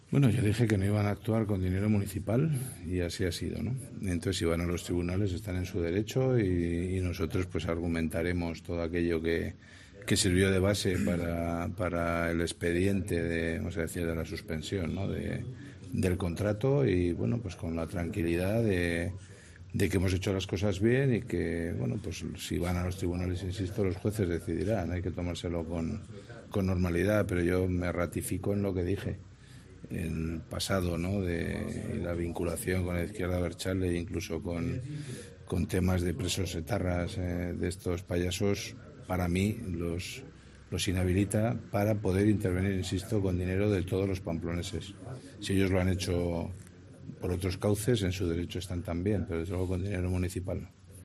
En declaraciones a los periodistas, Maya ha recordado que él dijo que estos payasos "no iban a actuar con dinero municipal y así ha sido" y ha señalado que si deciden acudir a los tribunales "nosotros argumentaremos todo aquello que sirvió de base para el expediente de la suspensión del contrato".